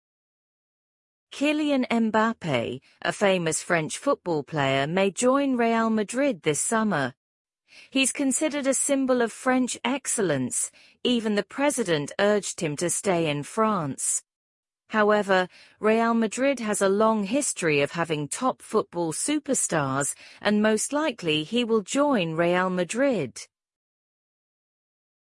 [CNN News Summary]
Other AI voices
Super Realistic AI Voices For Your Listening Practice